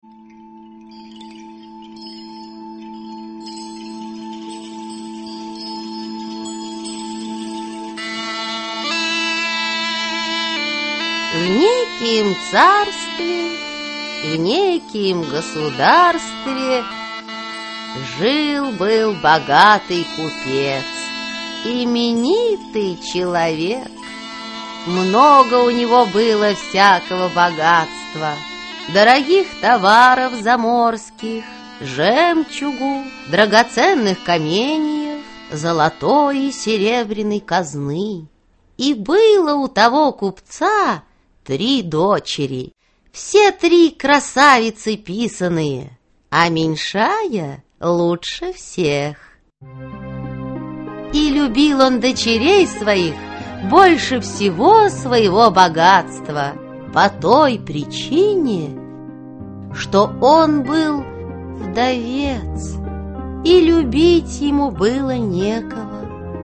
Аудиокнига Аленький цветочек (спектакль) | Библиотека аудиокниг
Aудиокнига Аленький цветочек (спектакль) Автор Сергей Аксаков Читает аудиокнигу Актерский коллектив.